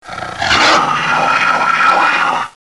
h_healstart.mp3